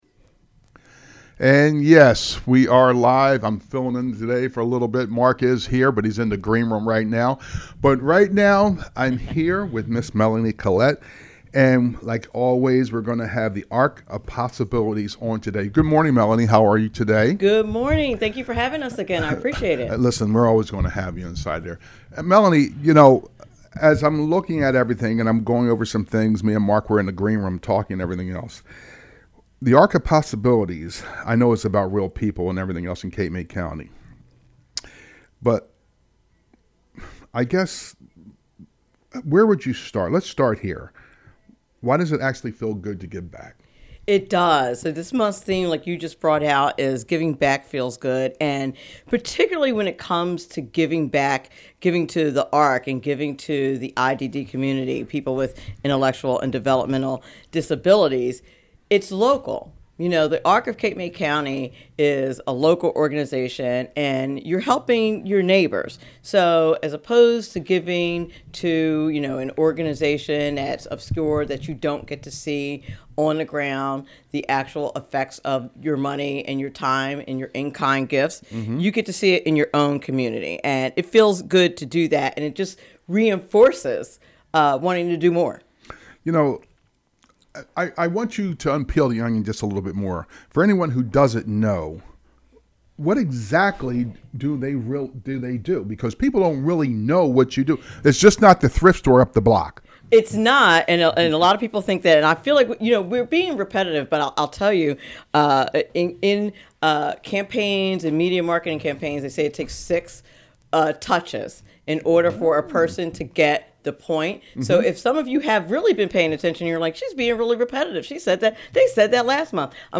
Great conversation on this month’s Arc of Possibility on 98.7 The Coast.